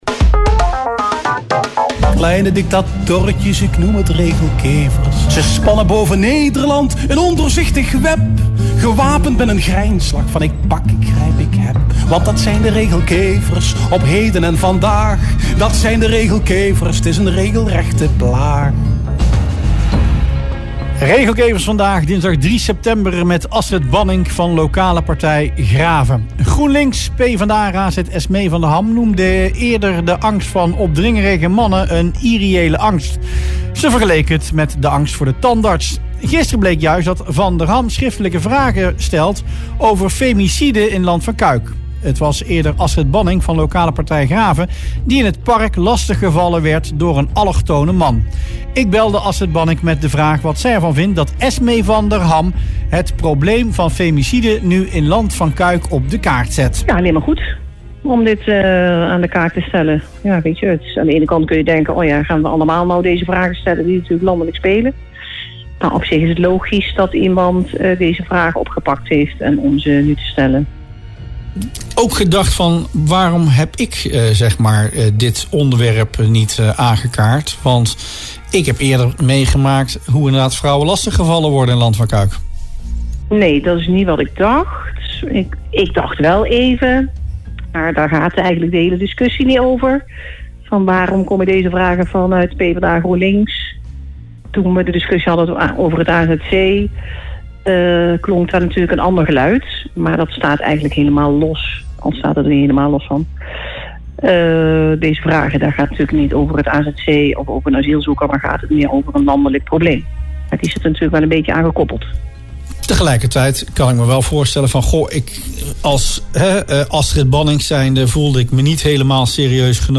Dat zei Bannink in radioprogramma Rustplaats Lokkant.